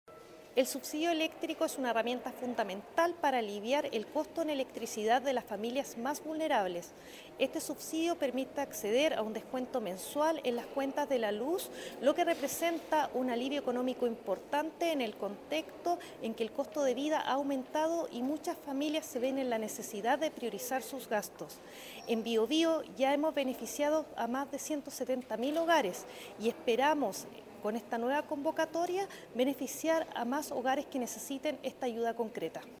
La seremi de Energía, Daniela Espinoza, destacó que ya se ha “beneficiado a más de 170 mil familias en la región del Biobío, y con esta nueva convocatoria, esperamos llegar a más hogares que necesiten esta ayuda concreta en sus cuentas de luz”.
cuna-01-subsidio-electrico.mp3